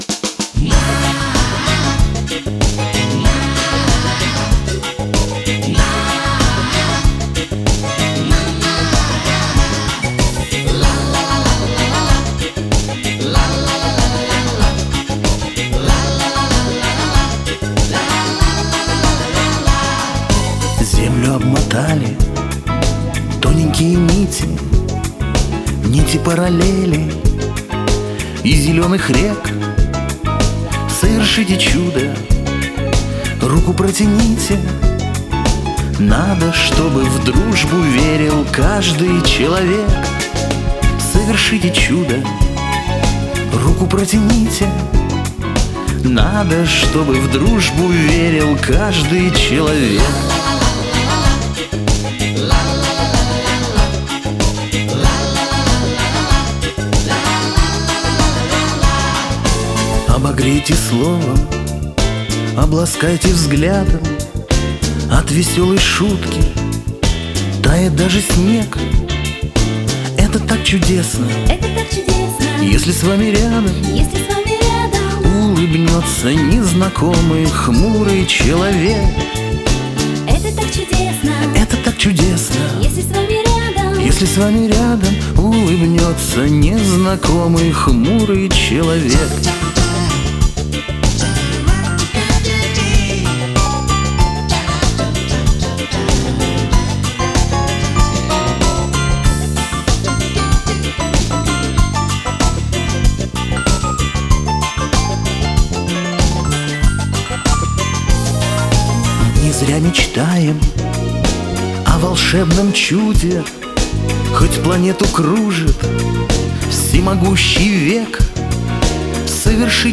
бэк вокал
вокал, бас-гитара, клавишные
альт-саксофон, клавишные
ударные, перкуссия